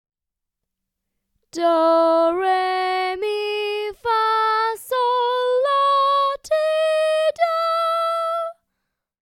The Noble Duke of York: Solfa Scale
Ex-2-solfa-scale.mp3